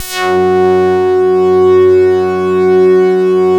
BAND PASS .3.wav